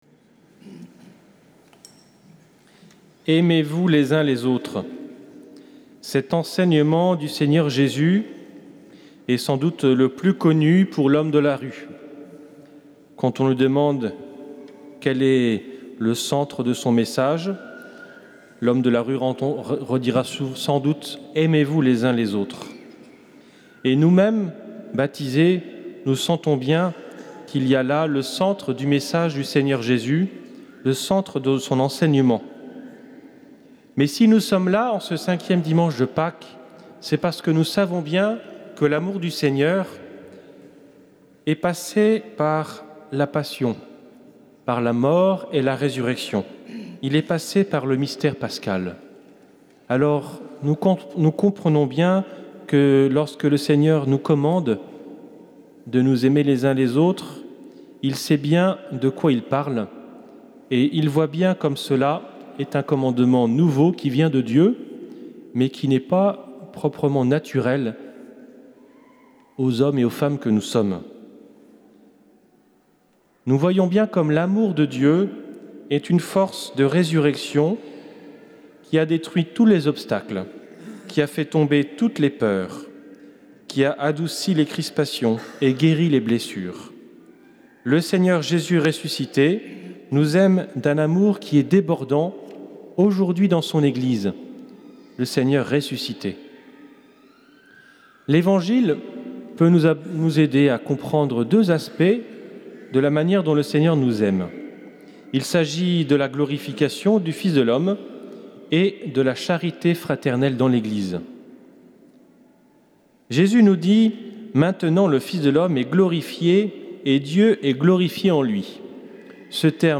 Homélie de Mgr Yves Le Saux